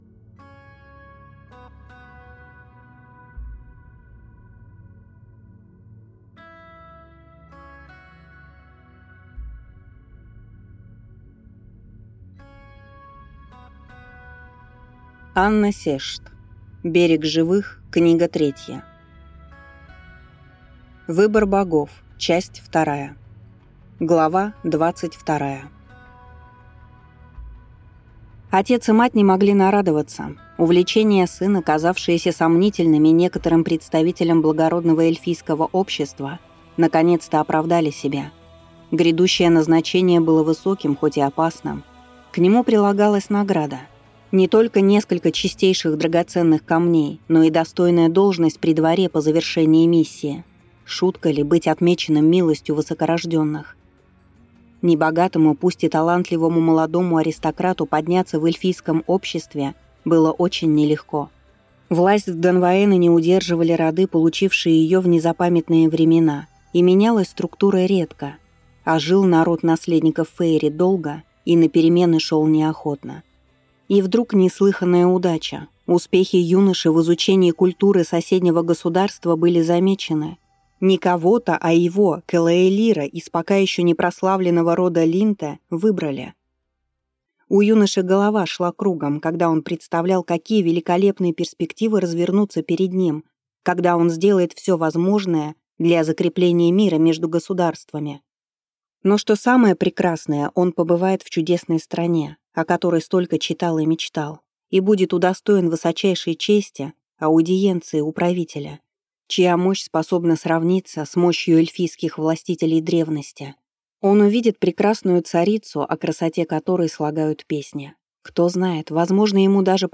Аудиокнига Берег Живых. Выбор богов. Книга вторая | Библиотека аудиокниг